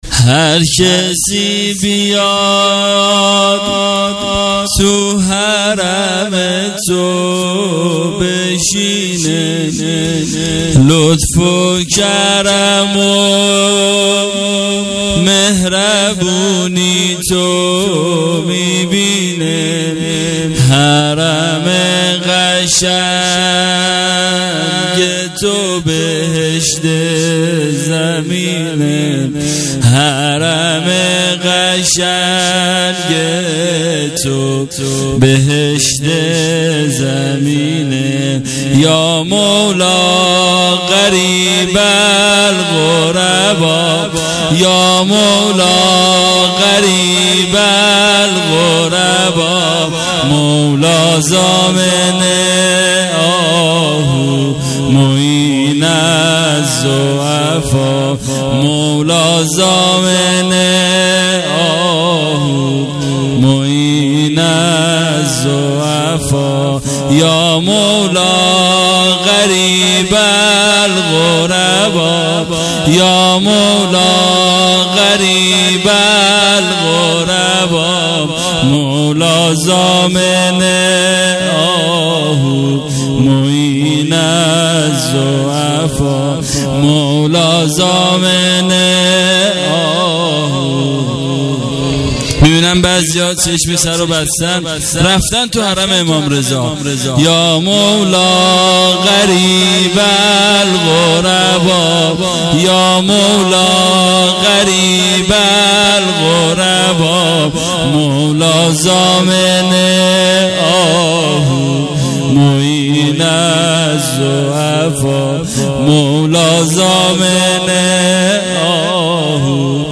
عزای شهادت امام رضا(ع)۹۸